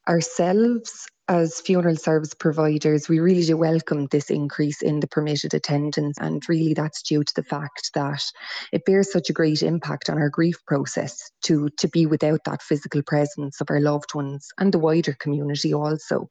Speaking to WLR News, Funeral director